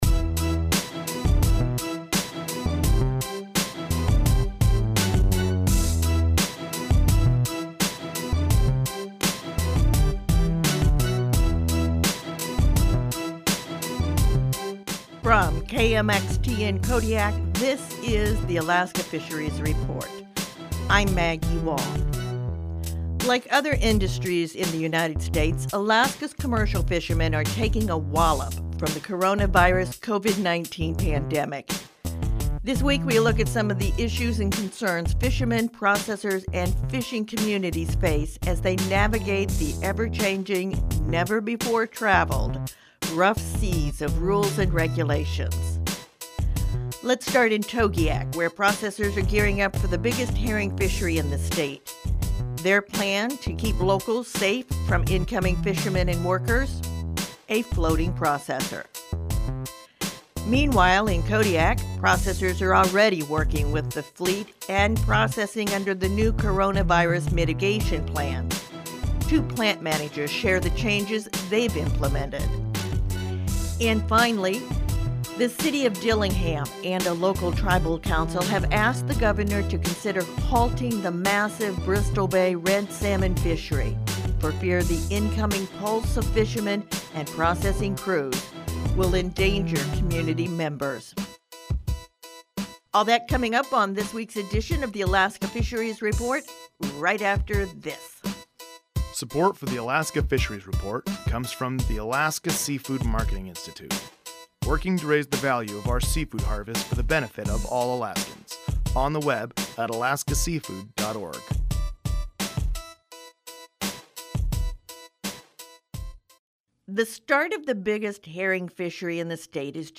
Two plant managers share the changes they’ve implemented.